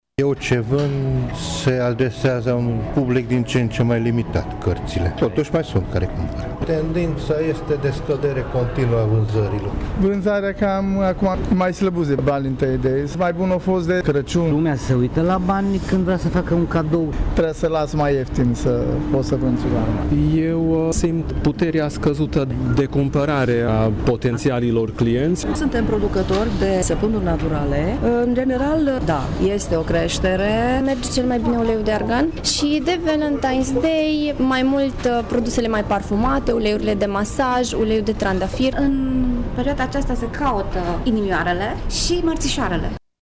Cei mai mulți dintre cei ce-și expun marfa remarcă o scădere continuă a vânzărilor în ultimii ani: